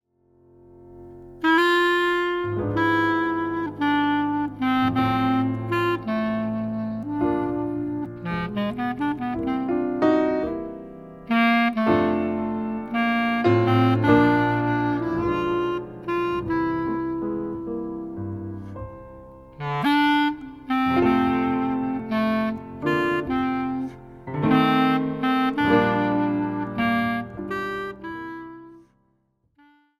今回もスタンダードナンバー、賛美歌で構成されバラードソング中心の選曲。
アコーディオンとギターの音色も魅力的なCDです。
Vibraphone
Piano
Bass
Drums
Accordion
Guitar